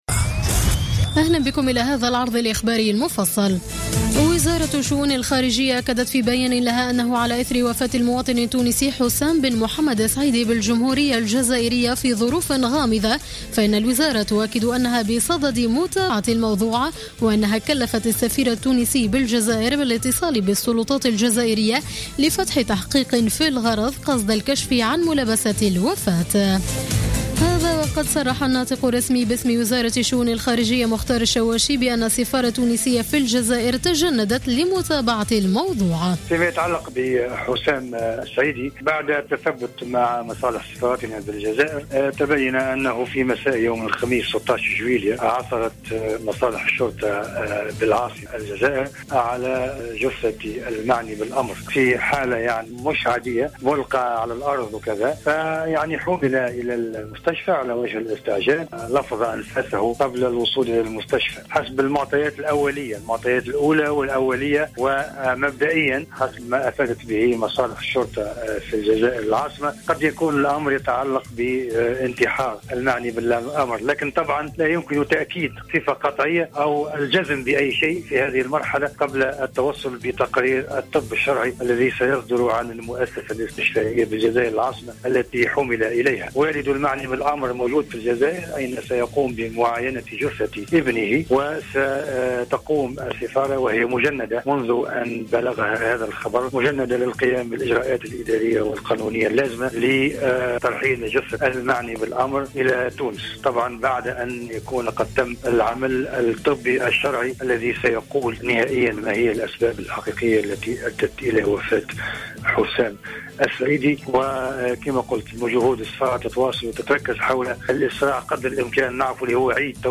نشرة أخبار منتصف الليل ليوم الأحد 19 جويلية 2015